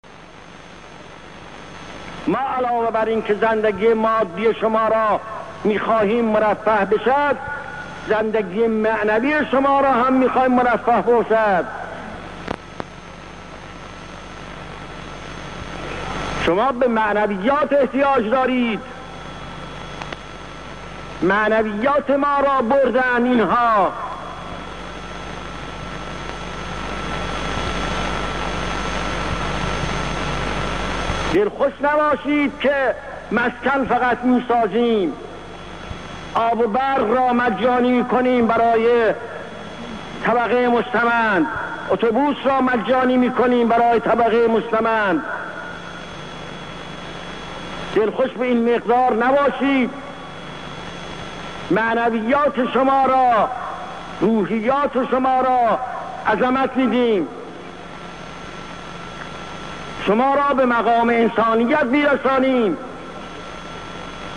حضرت امام (ره) در اجتماع مدرسه فیضیه در تاریخ ۱۲ اسفند ۱۳۵۷ بود که فرمودند :
نطق مهم امام خمینی در اجتماع مدرسه فیضیه